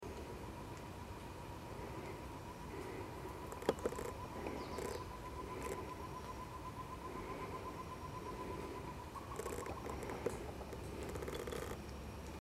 Tovaca-campainha (Chamaeza campanisona)
Nome em Inglês: Short-tailed Antthrush
Localidade ou área protegida: Parque Nacional Iguazú
Condição: Selvagem
Certeza: Gravado Vocal